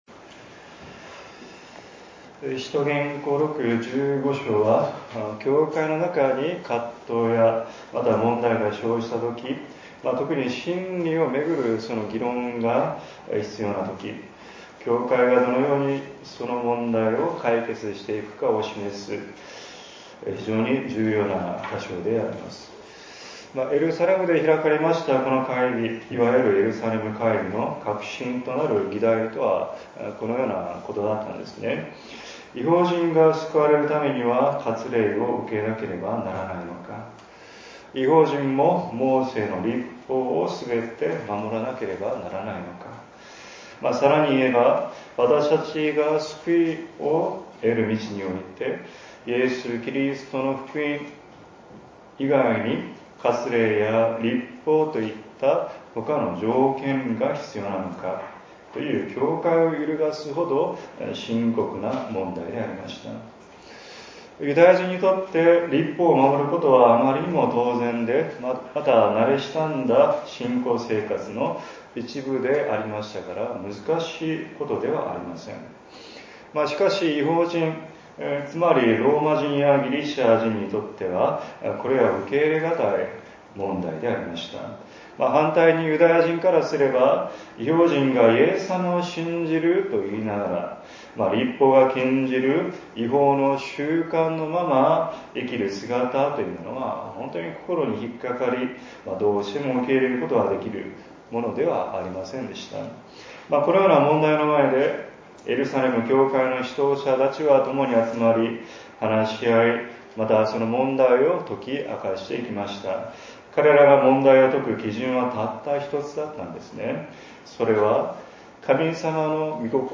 説 教 「万事を益とされる神」